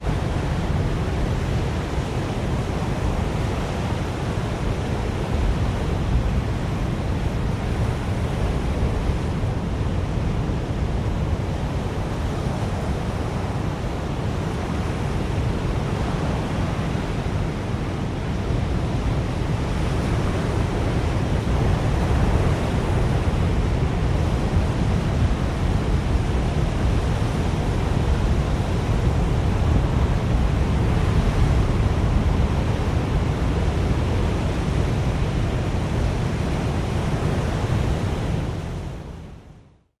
am_hurricane_01_hpx
Hurricane force winds blow loudly. Winds, Hurricane Weather, Hurricane Storm, Hurricane